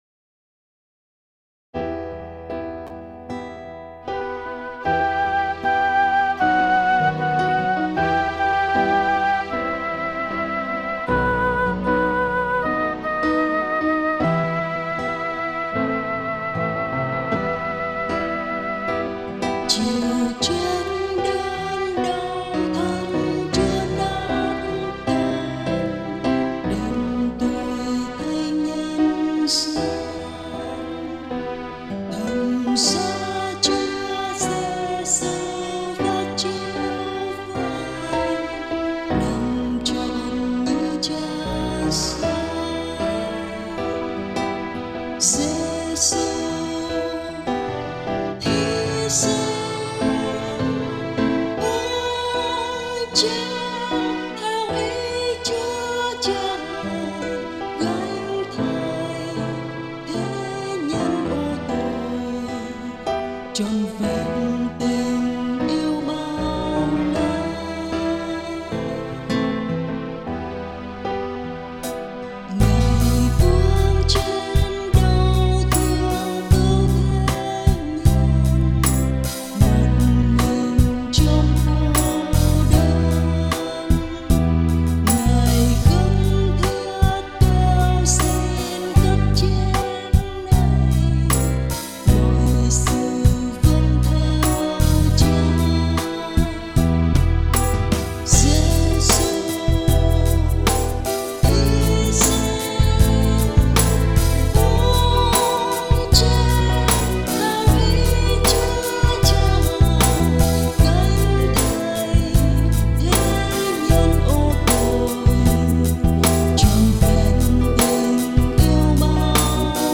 Nhạc Thánh